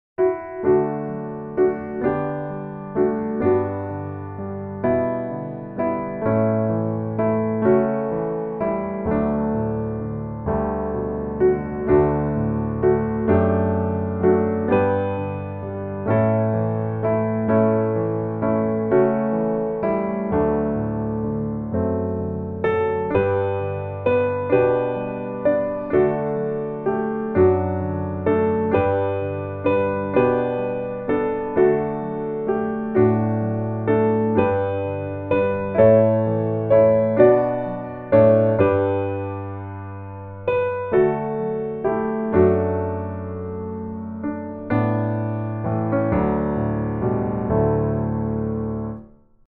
D Major